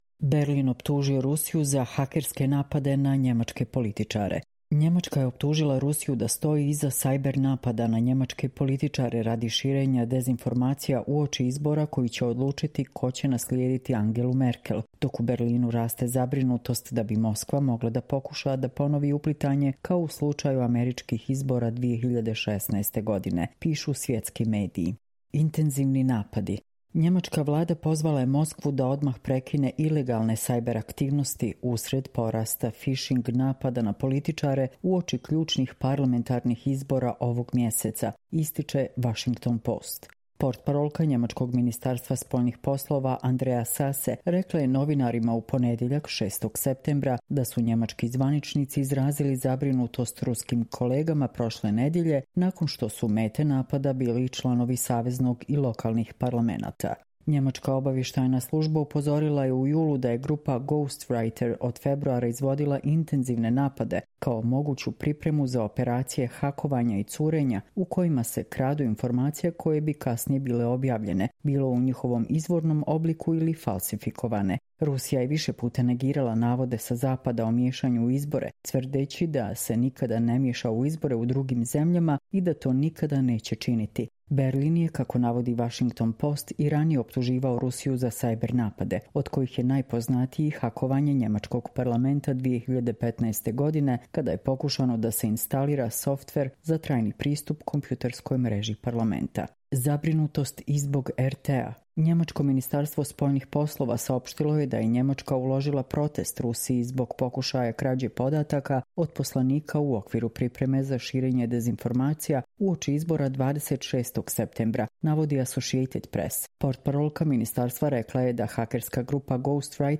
Čitamo vam: Berlin optužio Rusiju za hakerske napade na nemačke političare